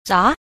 b. 雜 – zá – tạp